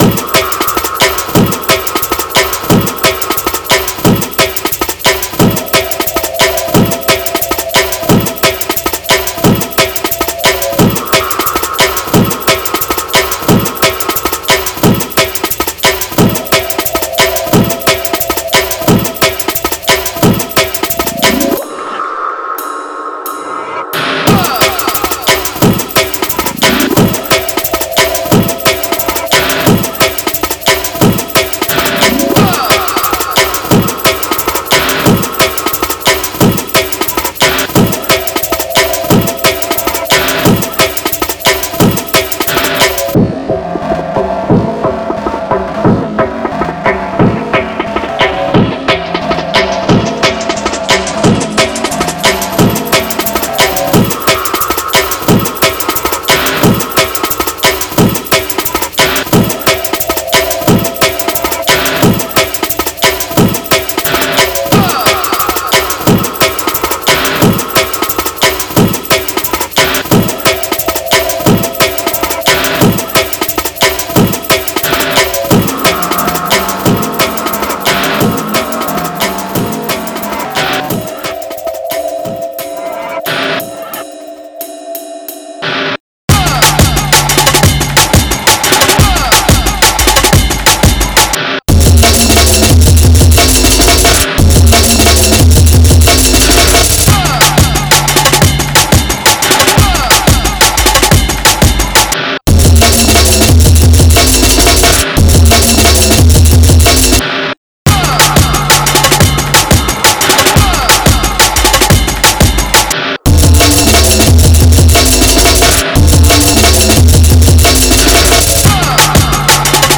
here's a (REALLY) rough mix of one of the tracks
smoked out rough mix.mp3